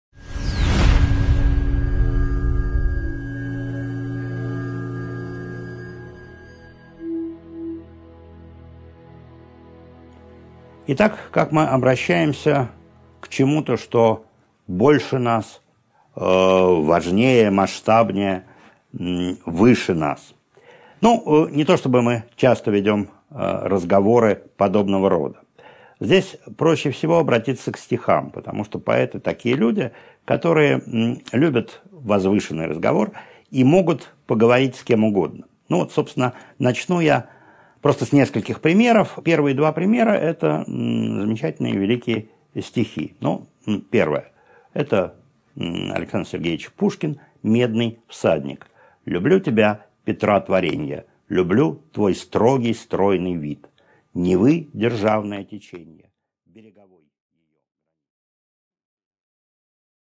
Аудиокнига 10.4 Обращение к высшей силе | Библиотека аудиокниг